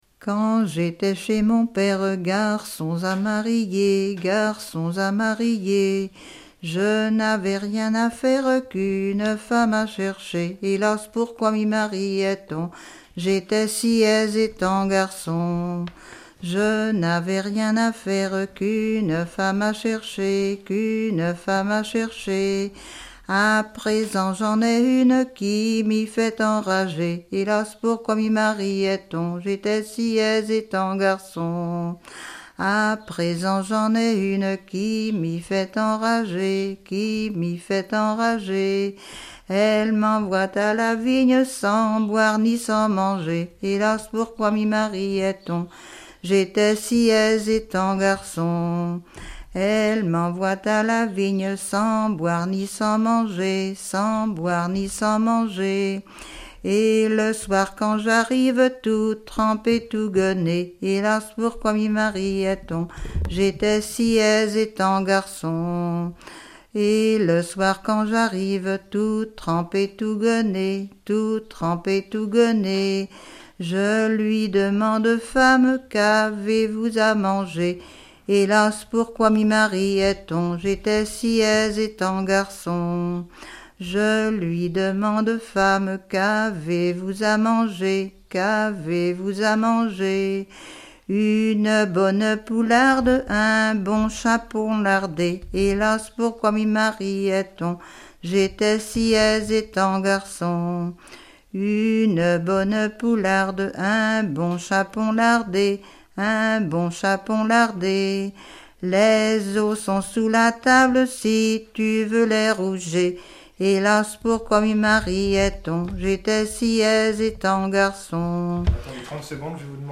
Genre laisse
chanson
Pièce musicale inédite